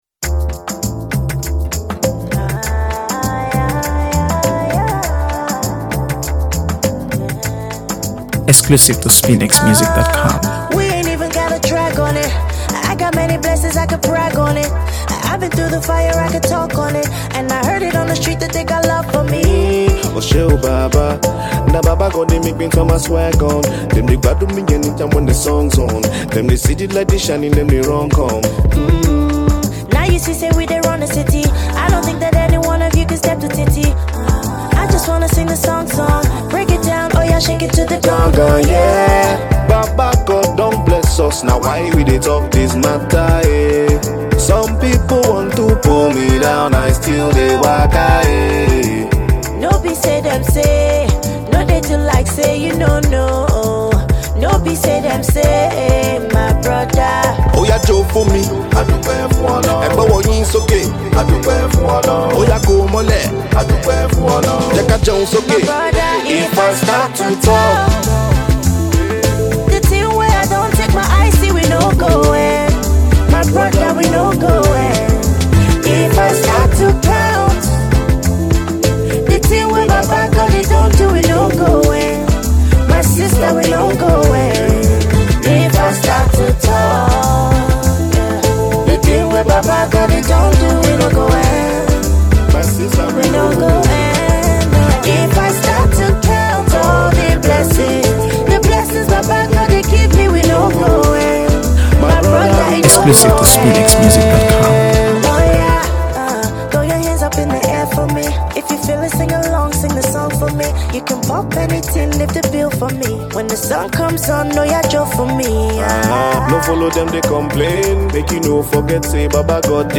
AfroBeats | AfroBeats songs
a beautiful and captivating song